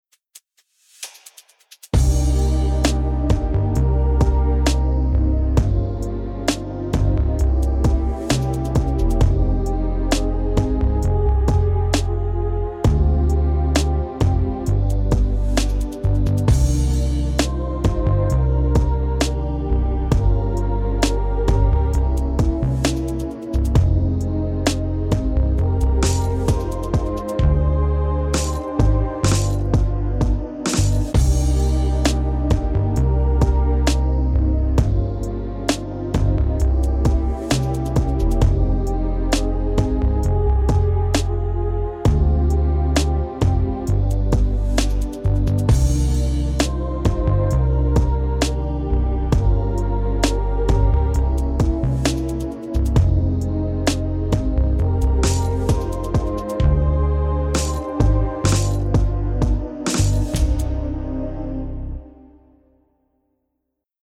Akkordprogression med modulation og gehørsimprovisation:
Lyt efter bassen, der ofte spiller grundtonen.
C instrument (demo)